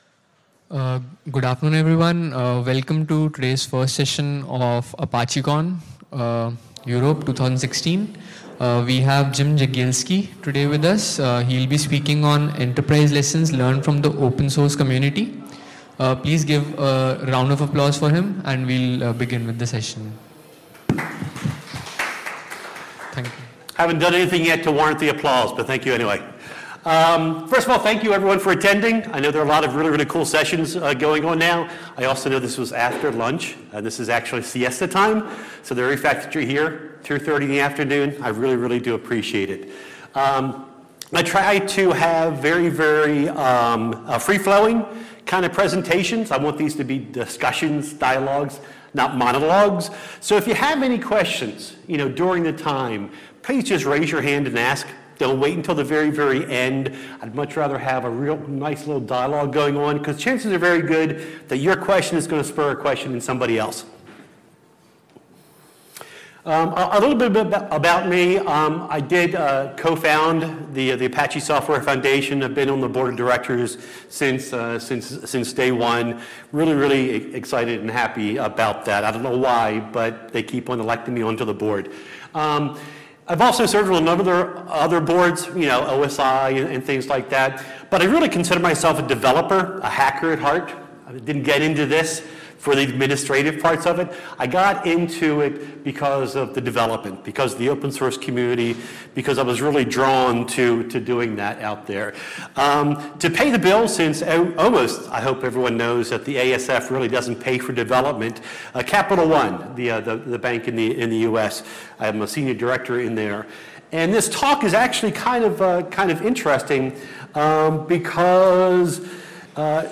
ApacheCon Seville 2016